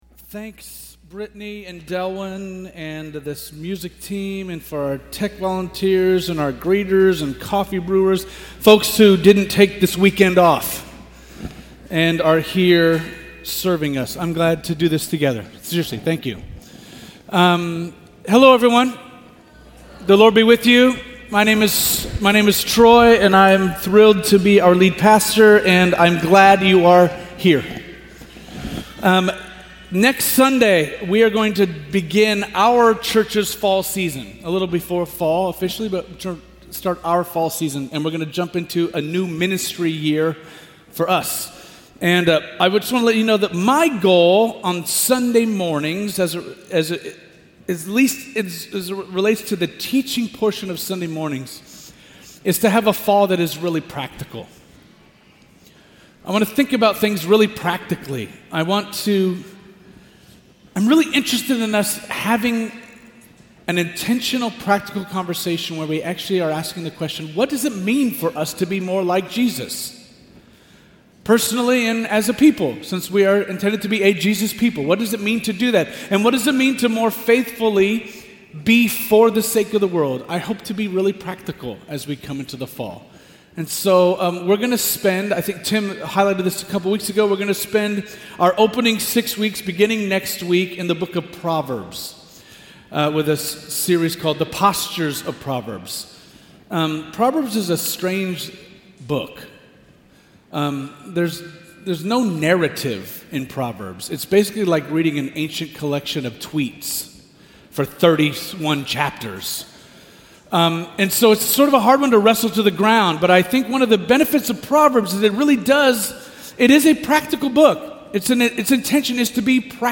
In the final Teaching of this summer series, we consider Judas as a relatable character in the Bible. Exercising some empathy and compassion, seeing Judas afresh invites us to think about how we treat and think about one another as we all continue to stumble forward together.